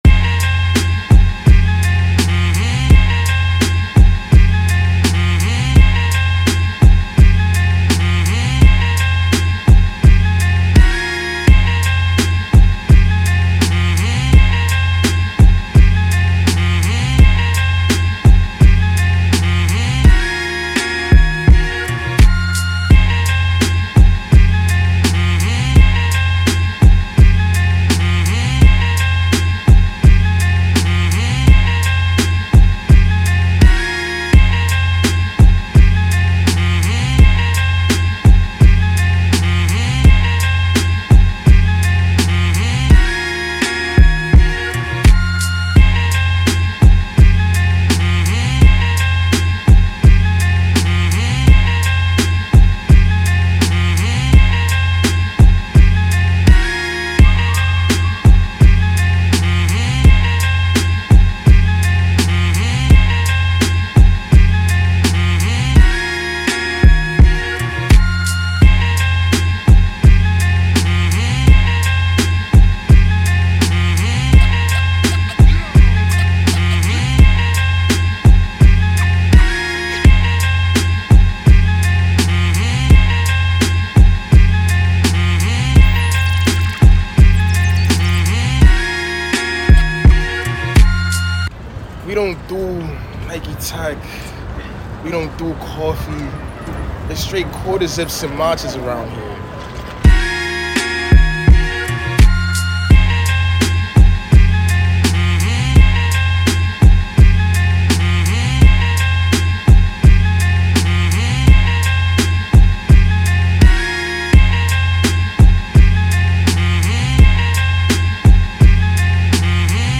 official instrumental
Rap Instrumentals